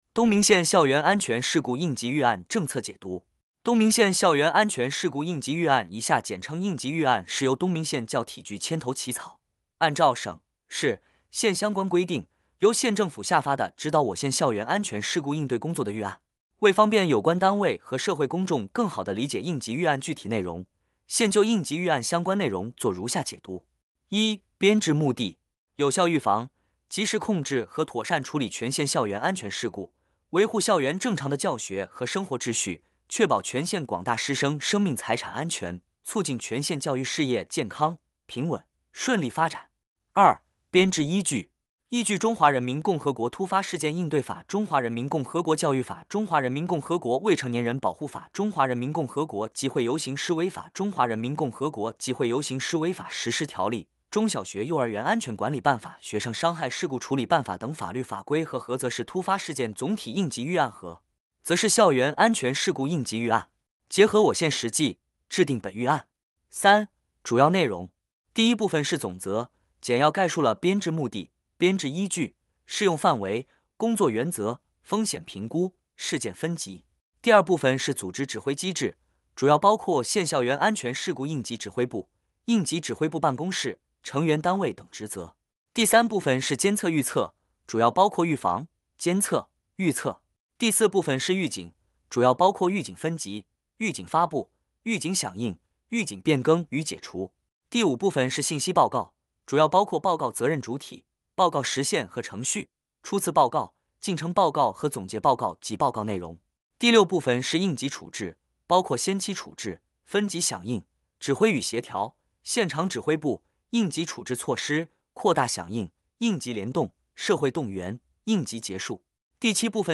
• 分　　类：音频解读